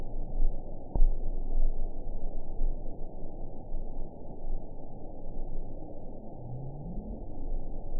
event 917963 date 04/23/23 time 23:39:07 GMT (12 months ago) score 8.81 location TSS-AB06 detected by nrw target species NRW annotations +NRW Spectrogram: Frequency (kHz) vs. Time (s) audio not available .wav